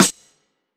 zsfg_snr.wav